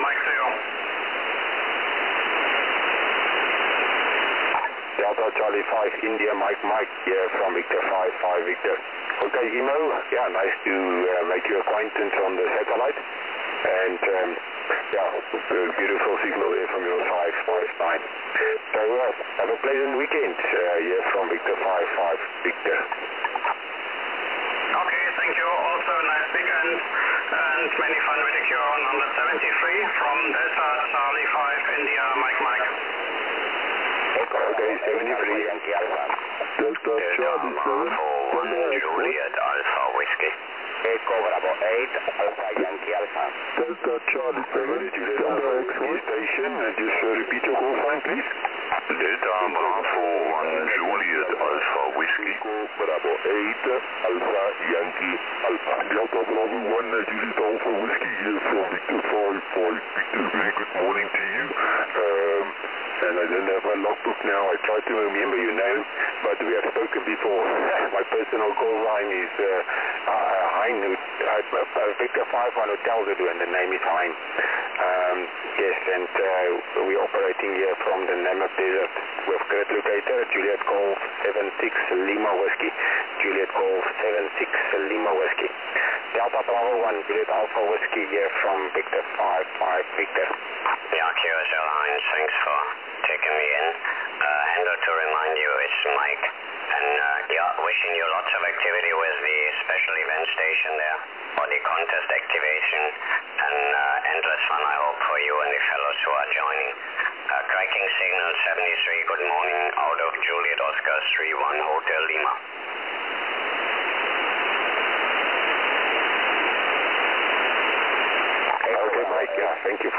QSO via QO-100